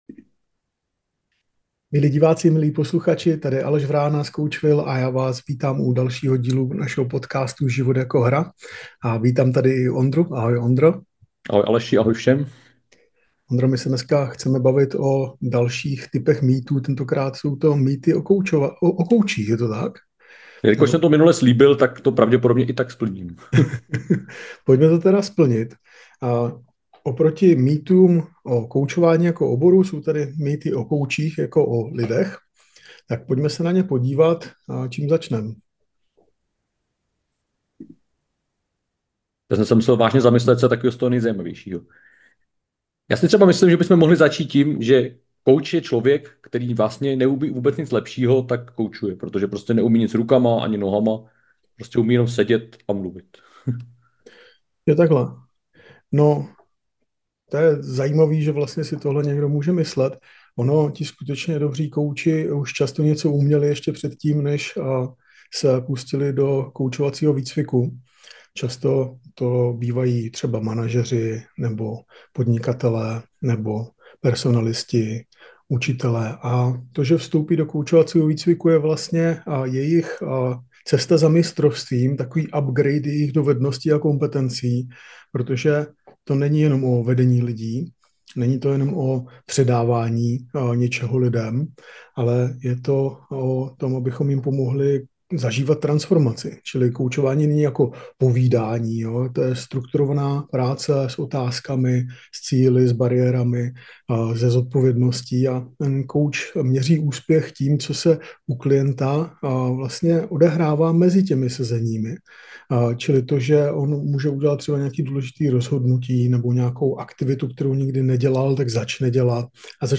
Uslyšíte otevřený rozhovor o tom, co profesionální koučování skutečně je, jak poznat kvalitního kouče a proč tolik lidí zaměňuje koučování za něco, čím ve skutečnosti není.